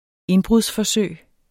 Udtale [ ˈenbʁuðs- ]